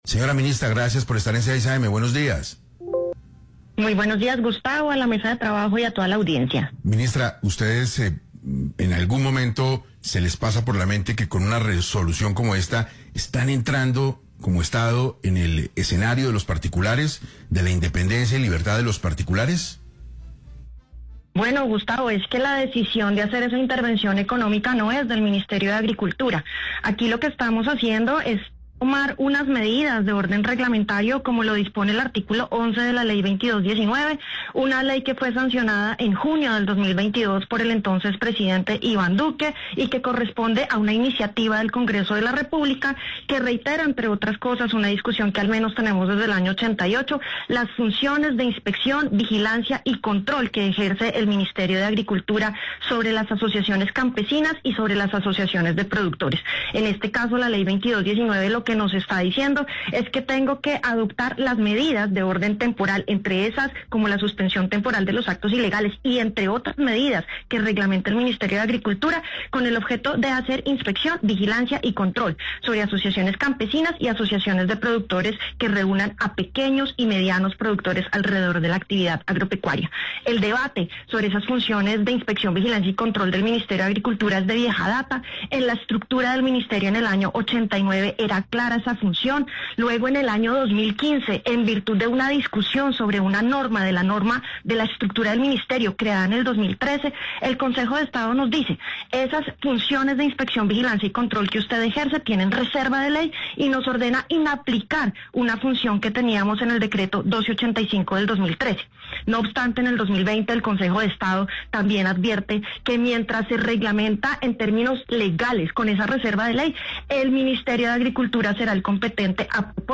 Frente a estos señalamientos, la ministra de Agricultura Martha Carvajalino dijo en 6AM que no se trata de una nueva norma, pero que si se busca reglamentar las funciones que empezará a tener el ministerio en materia de vigilancia.
Finalizando la entrevista, la ministra reiteró que las organizaciones están en todo su derecho de reclamar si se vulnera el debido proceso en las labores de vigilancia.